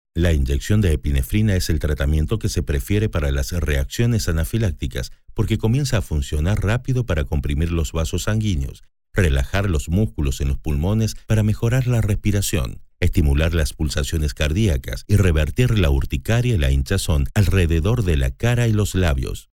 Male
Spanish (Latin American)
Adult (30-50)
Si esta buscando destacar un producto, servicio y desea que su mensaje se escuche, mi voz es la que necesita. Caliente, amable, de confianza, autorizada, divertido, creíble, positiva, enérgica, optimista.
Medical Narrations
Neumann Mic Narration Medical